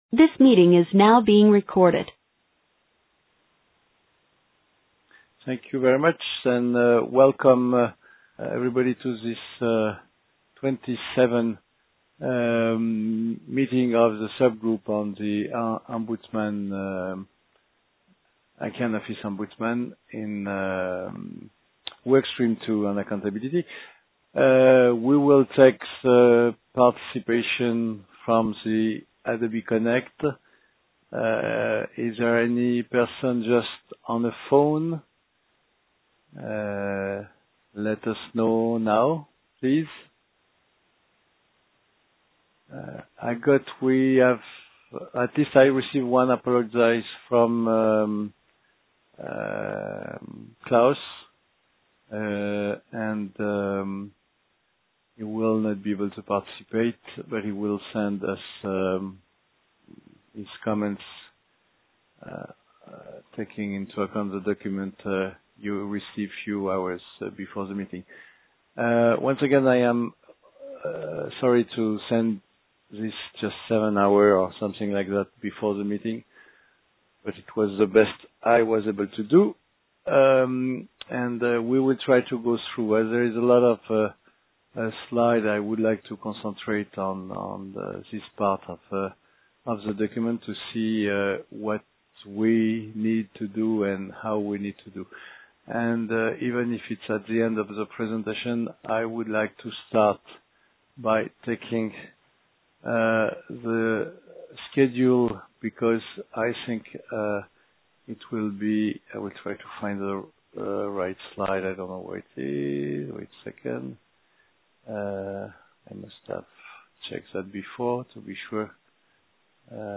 This call is recorded.